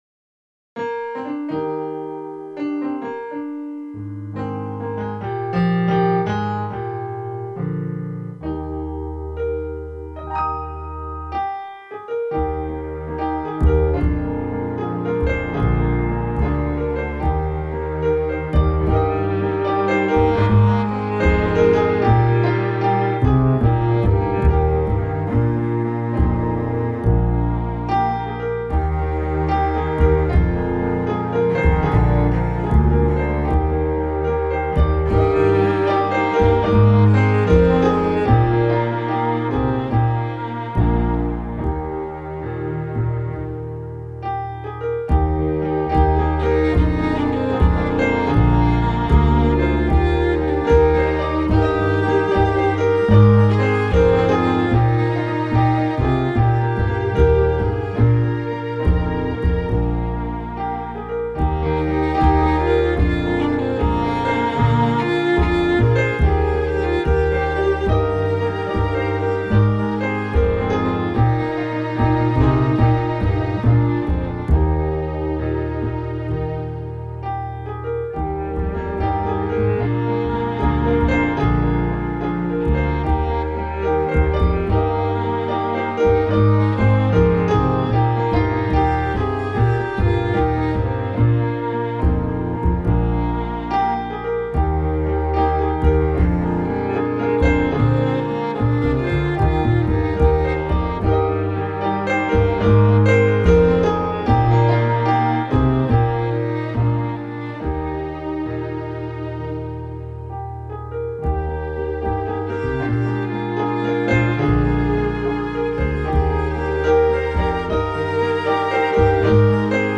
Accompagnement de la chanson
accomp_pour_des_prunes.mp3